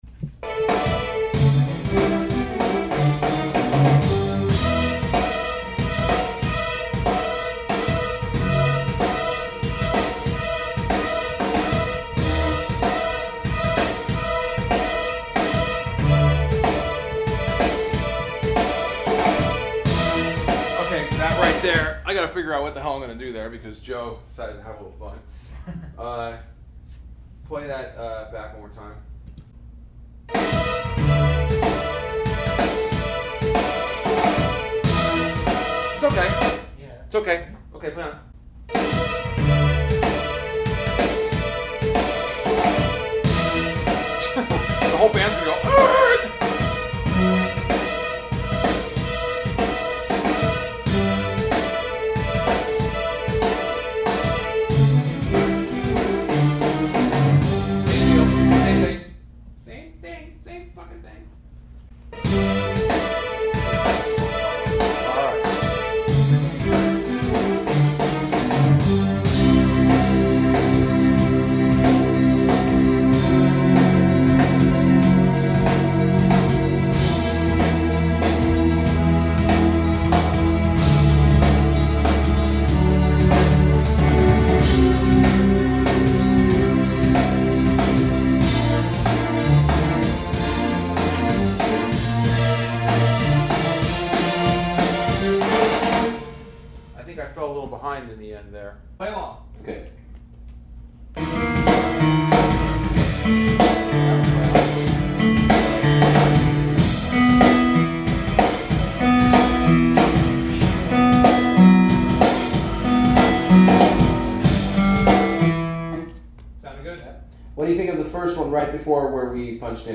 Lotsa lotsa keyboard for yours truly on this bit.